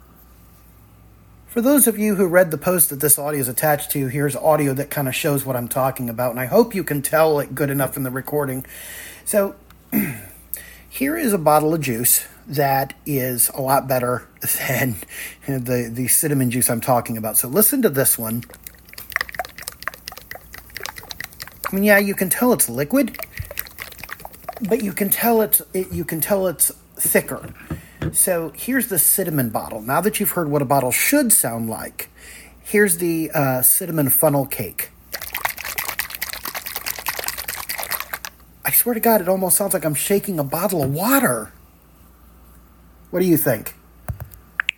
this is my final answer. the cinimon funnel cake I got locally sucks! my main reason for saying this? it is way too liquidy! I really need to find out what company makes it. when you shake the bottle it almost sounds like I am shaking water!